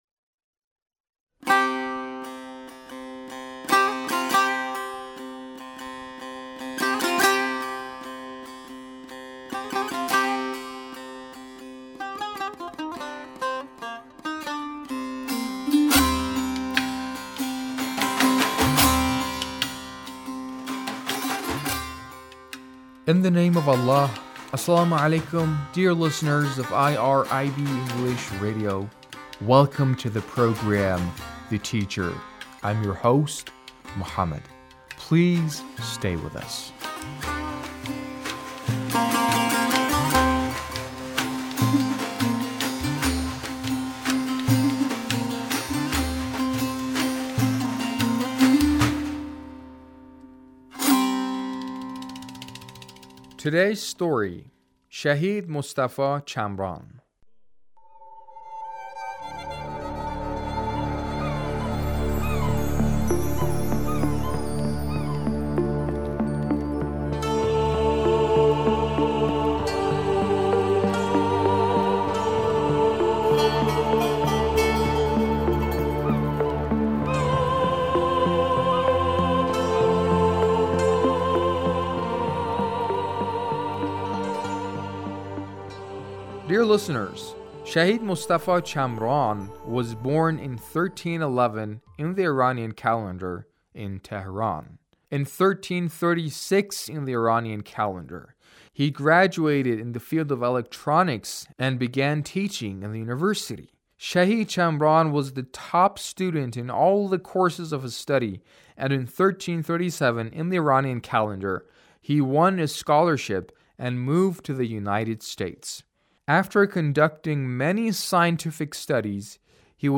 A radio documentary on the life of Shahid Mostafa Chamran